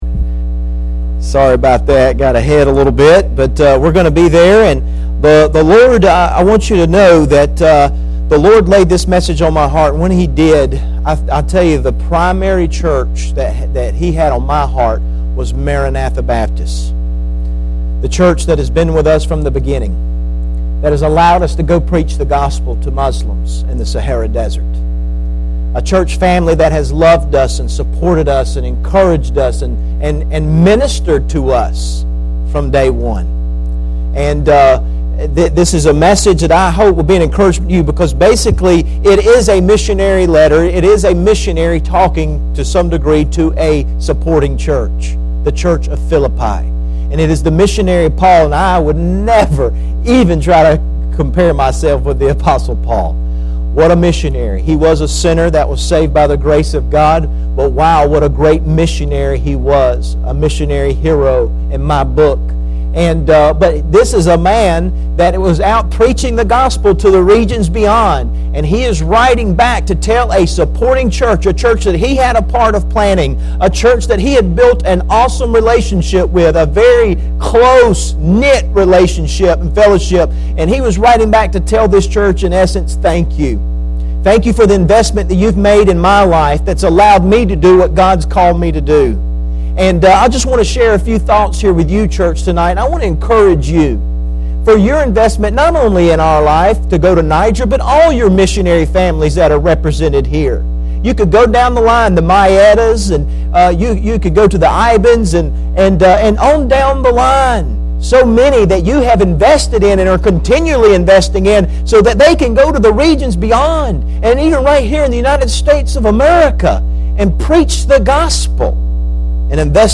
Passage: Philippians 4:14-19 Service Type: Sunday PM